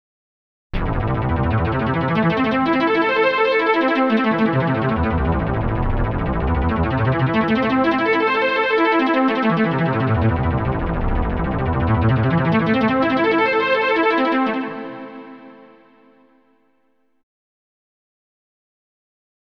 04_specialsfx_17_SQ.wav